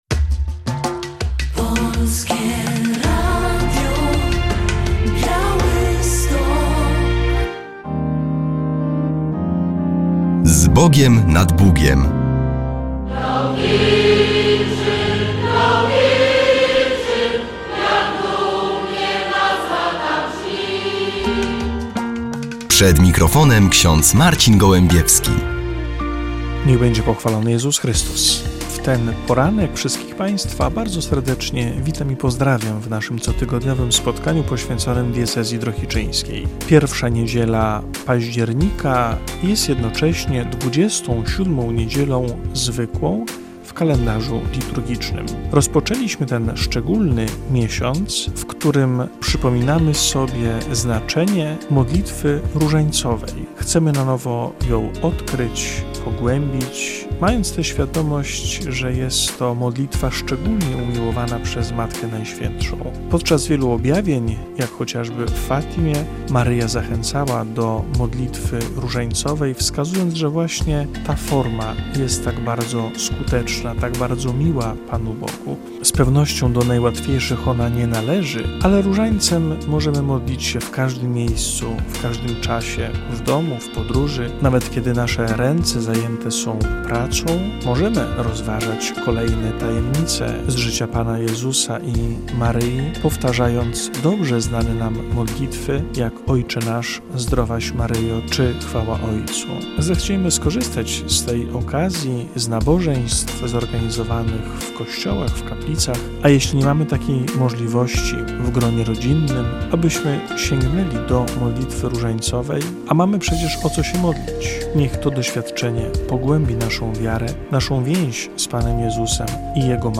W audycji relacja z inauguracji roku akademickiego w Wyższym Seminarium Duchownym w Drohiczynie.